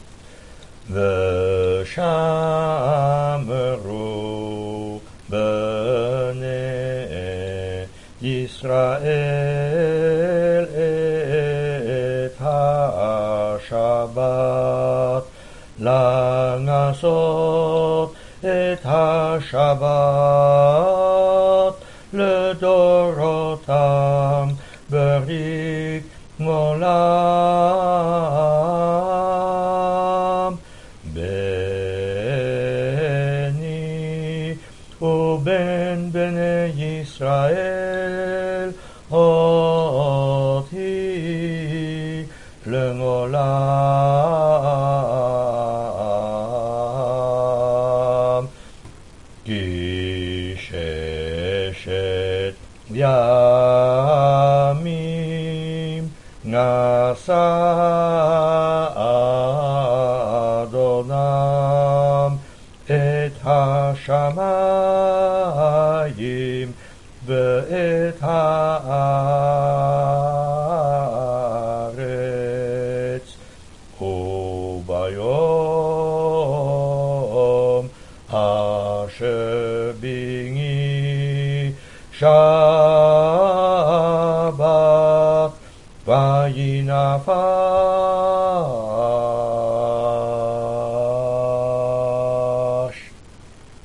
Mind that most recordings were made by non professional Chazzanim and thus are not 100% according to the sheet music.
Festive Veshameru.mp3